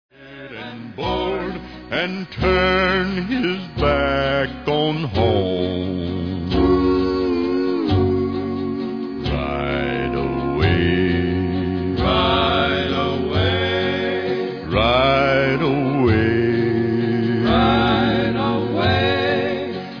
Western songs from his earliest sessions